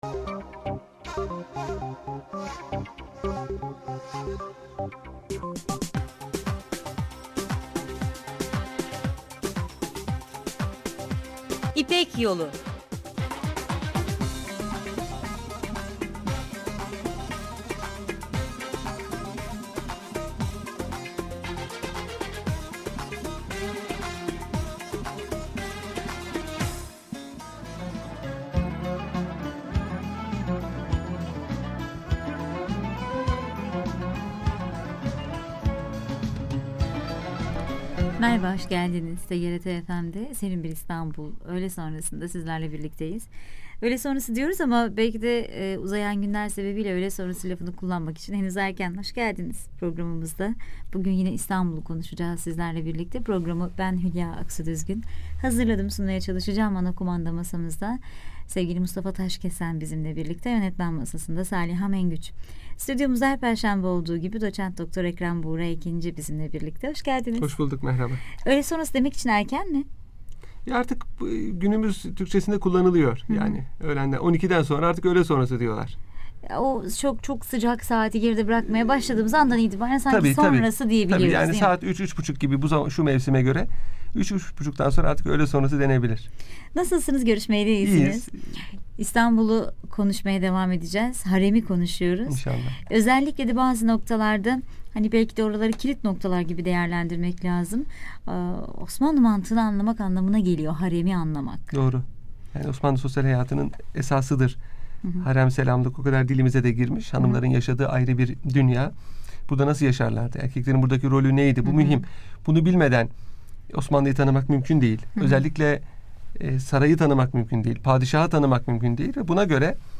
Radyo Programi - Saray Haremi.wav